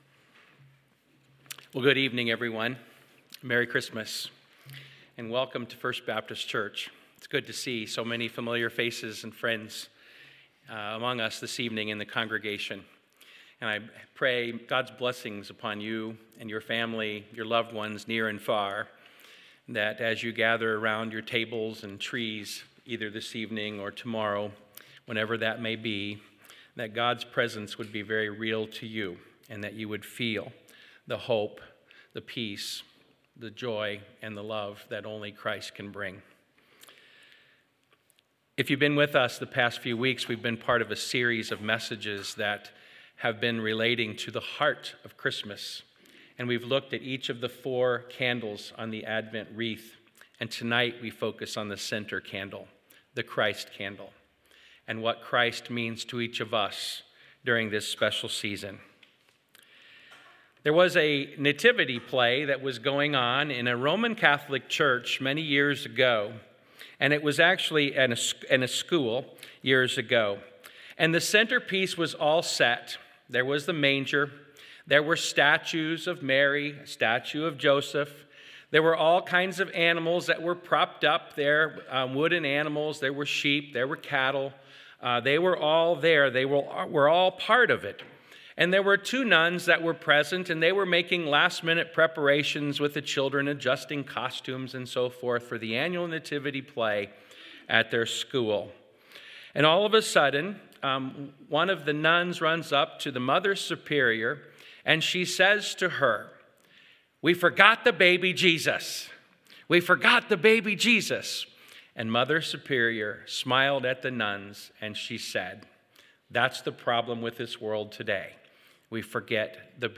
Sermon: Jesus Is the Heart of Christmas